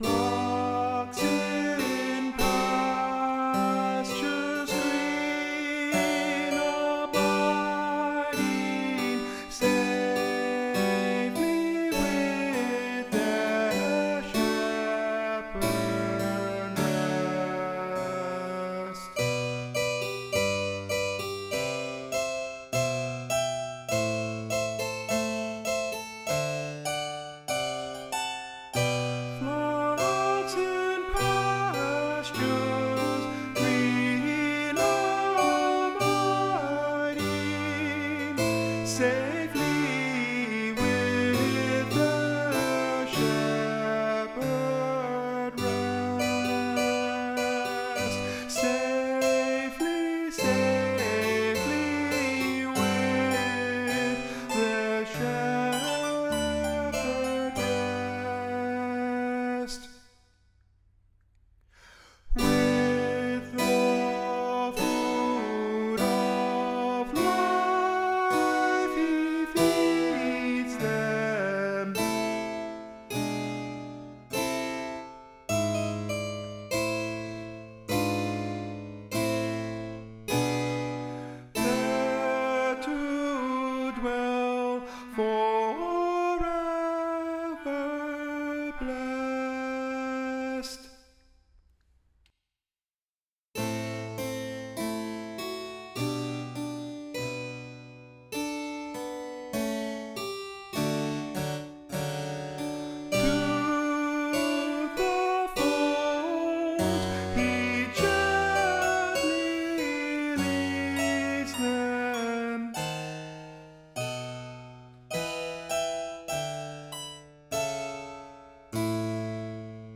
Tenor   Instrumental | Downloadable